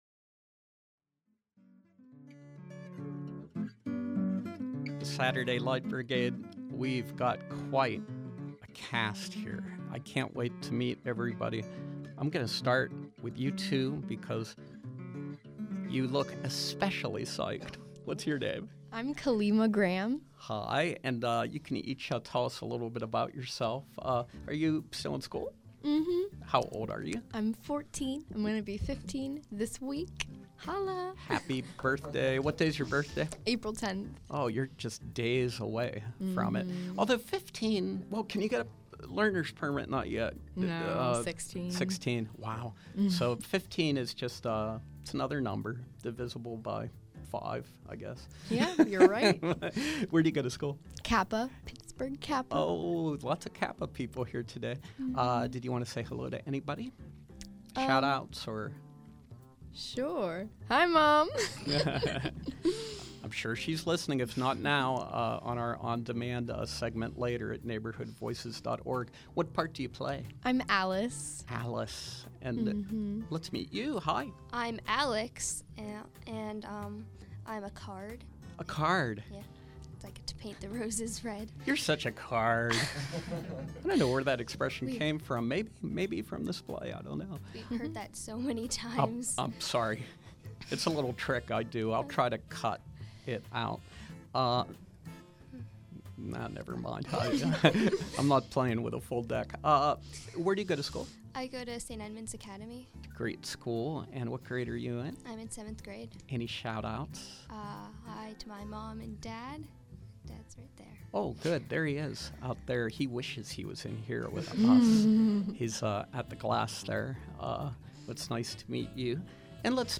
From 4/5/14: Gemini Theater with a scene from its interactive version of Alice in Wonderland, 4/5 to 4/27